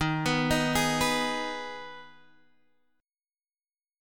D#sus4#5 chord